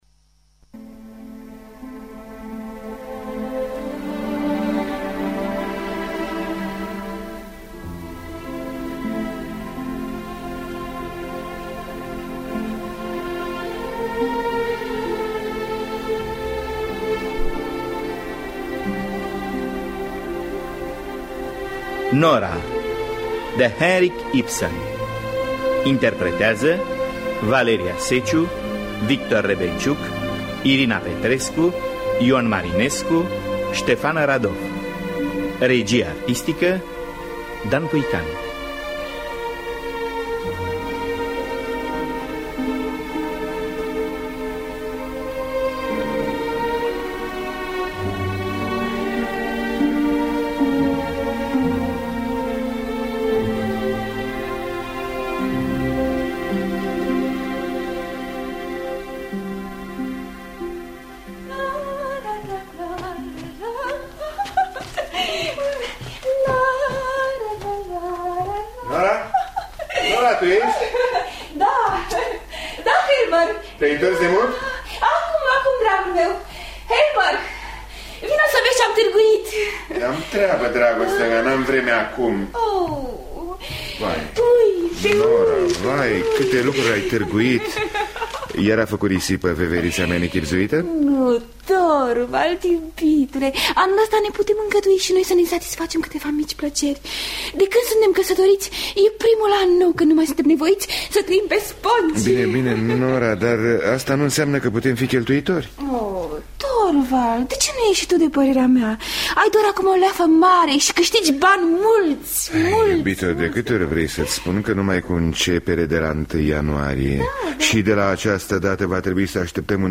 Adaptarea radiofonică
Înregistrare din anul 1954.